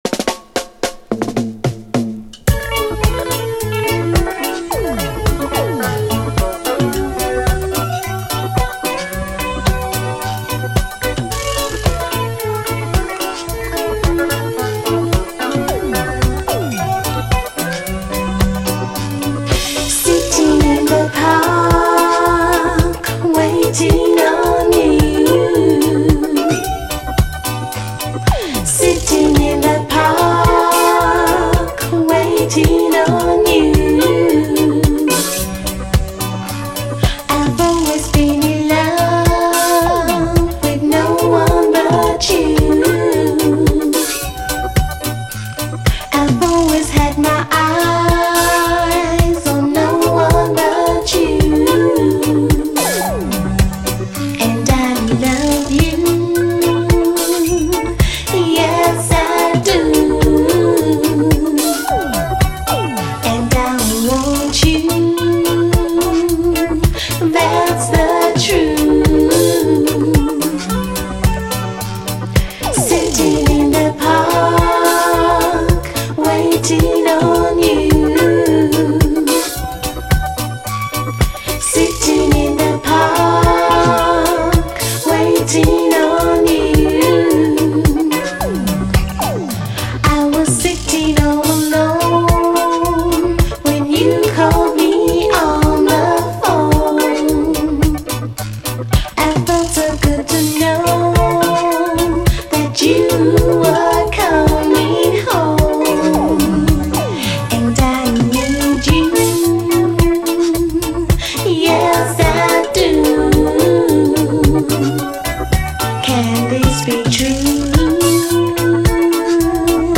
REGGAE
ギラギラと鈍い光を放つシンセ・アレンジがカッコいい、女性グループの胸キュンUKラヴァーズ！
試聴ファイルはこの盤からの録音です
ピュイーン＆ギラギラと鈍い光を放つシンセ・アレンジが聴き所！もちろん女性コーラス・ハーモニーも非常に愛らしい。